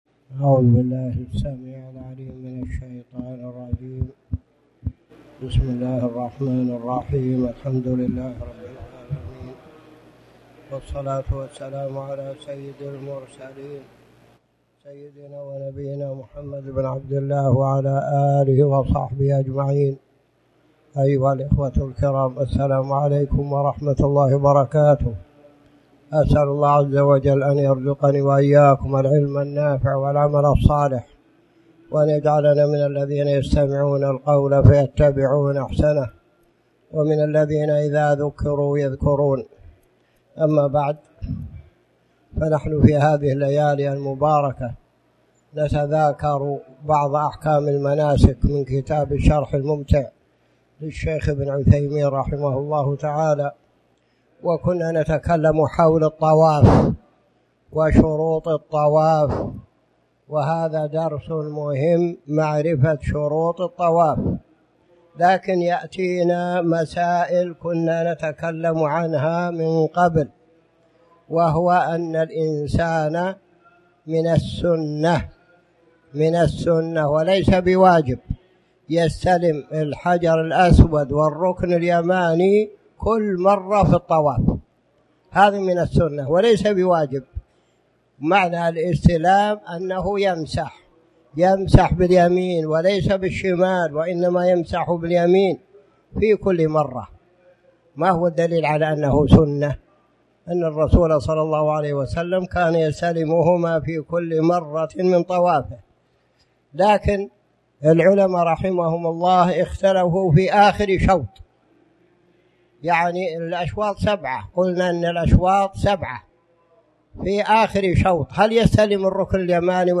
تاريخ النشر ٢٥ ذو الحجة ١٤٣٨ هـ المكان: المسجد الحرام الشيخ